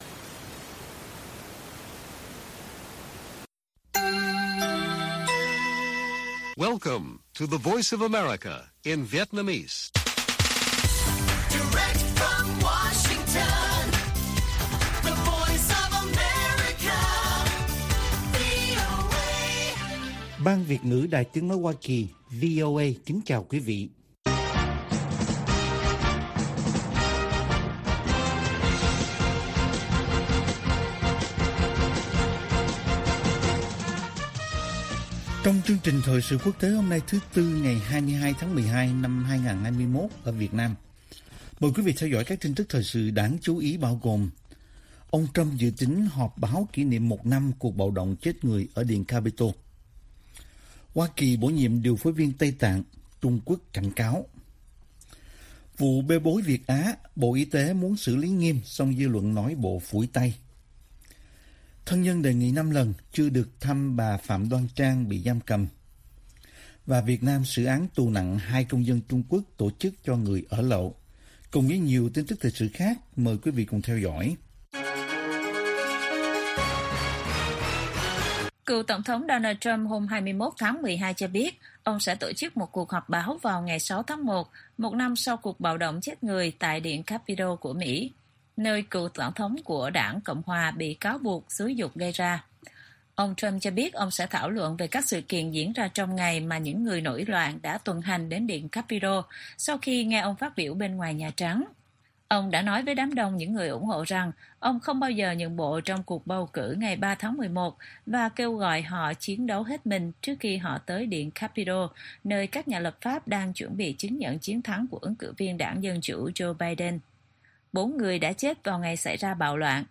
Bản tin VOA ngày 22/12/2021